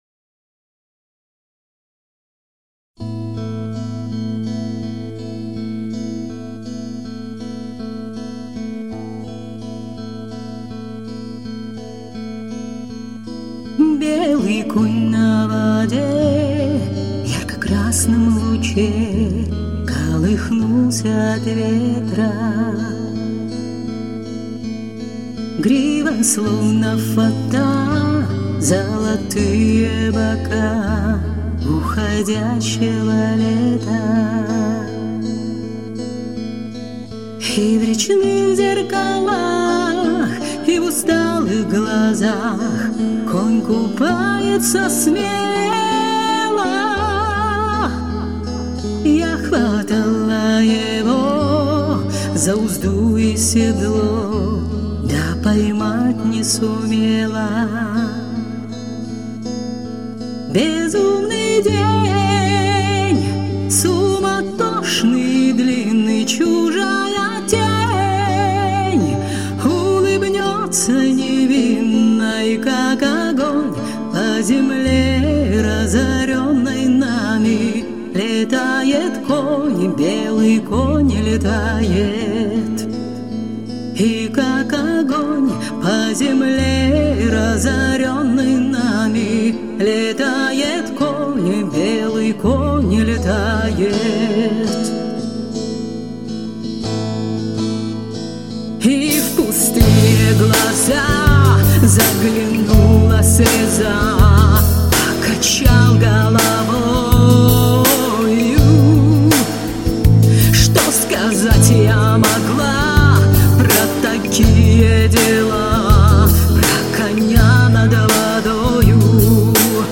Здесь интересное переплетение народного стиля и рока.
Голос как ручеек льется.